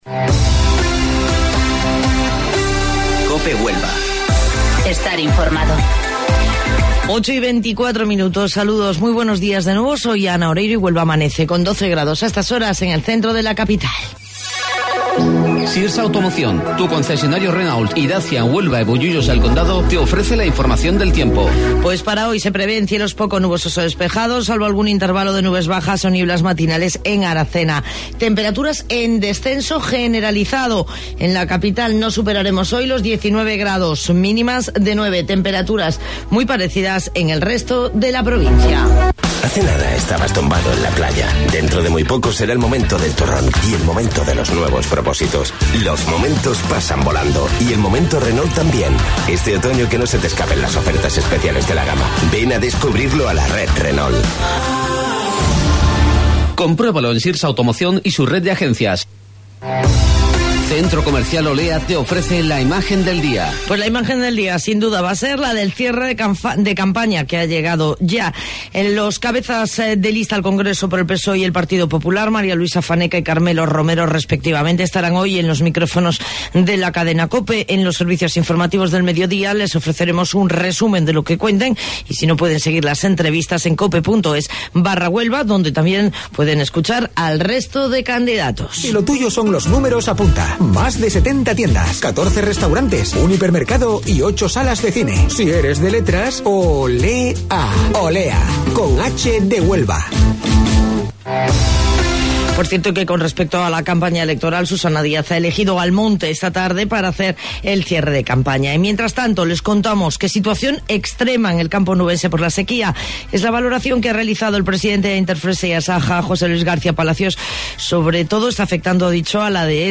AUDIO: Informativo Local 08:25 del 8 de Noviembre